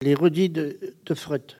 Localisation Sallertaine
Enquête Arexcpo en Vendée
Catégorie Locution